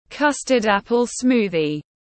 Sinh tố mãng cầu tiếng anh gọi là custard-apple smoothie, phiên âm tiếng anh đọc là /kʌstərd ˈæpəl ˈsmuːðiz/
Custard-apple smoothie /kʌstərd ˈæpəl ˈsmuːðiz/